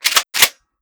fps_project_1/30-30 Lever Action Rifle - Lever 001.wav at c17ccea3e75caf1d3e5a57bfd9b19bd1429ff239